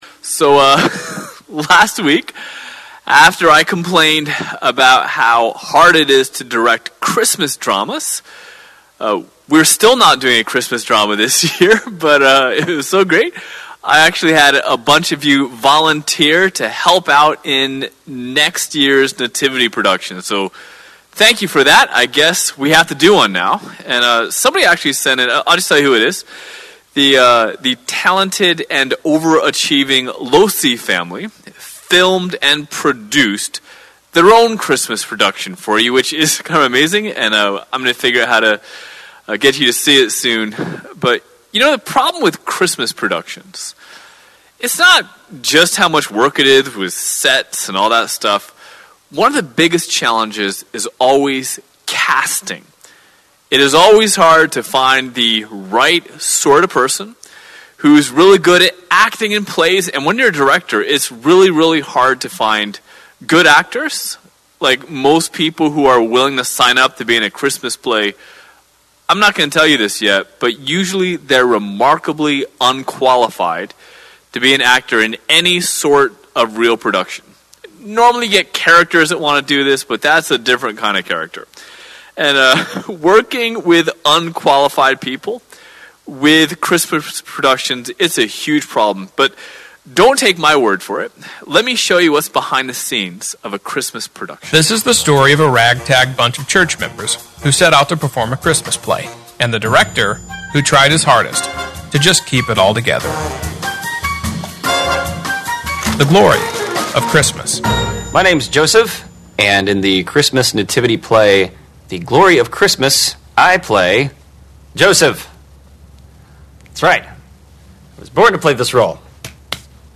SermonDec6th.mp3